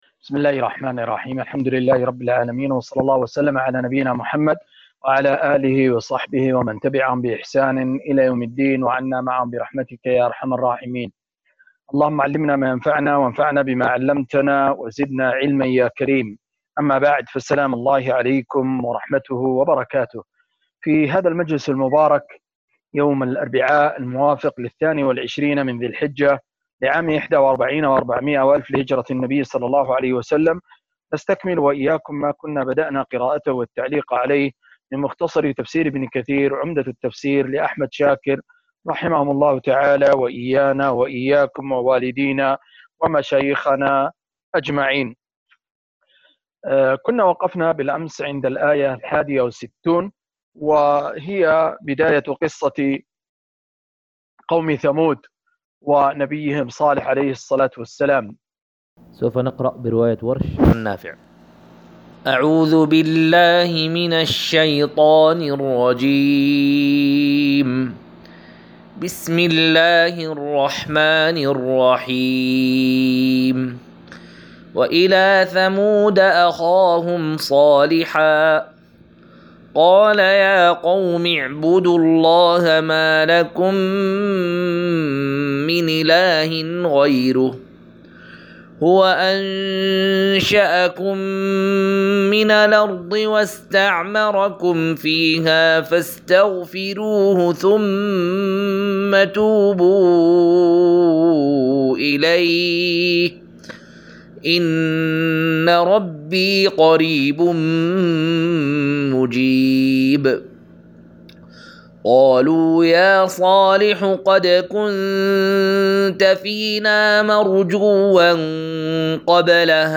216- عمدة التفسير عن الحافظ ابن كثير رحمه الله للعلامة أحمد شاكر رحمه الله – قراءة وتعليق –